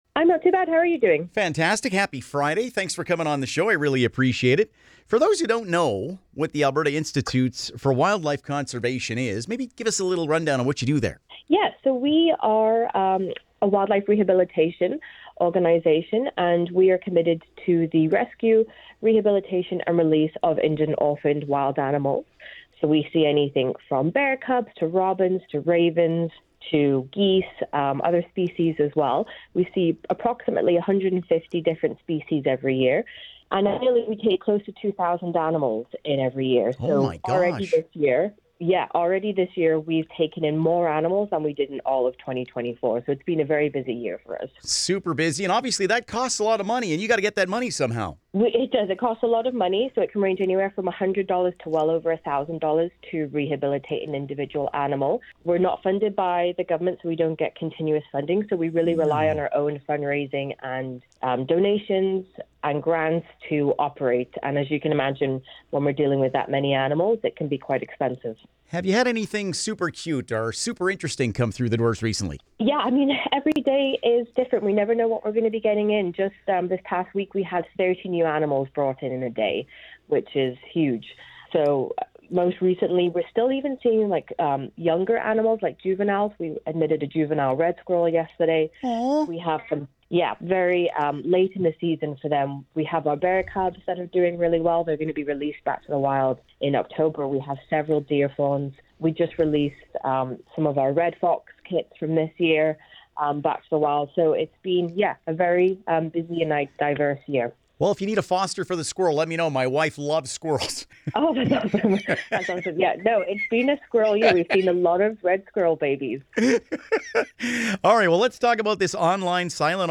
ab-institue-for-wildlife-con-interview.mp3